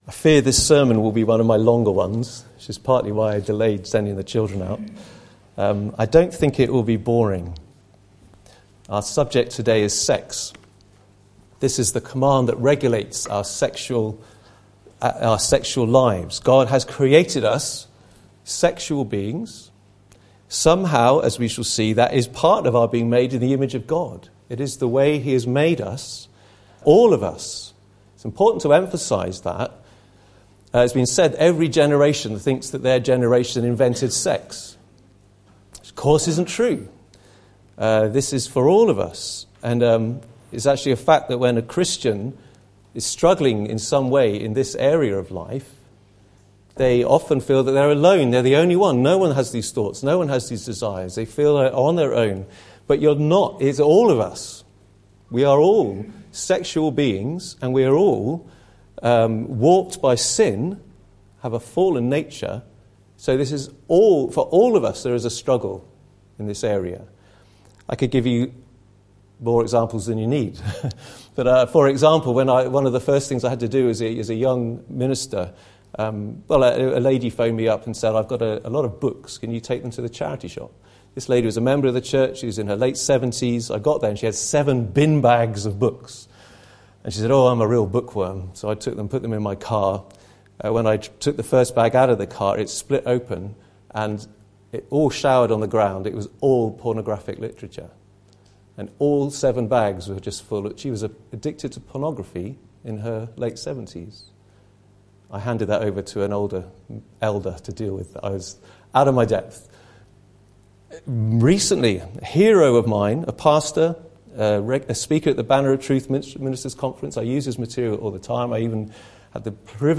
The 10 Commandments Passage: Exodus 20:14, Proverbs 5:15-23, 1 Corinthians 6:15-7:5 Service Type: Sunday Morning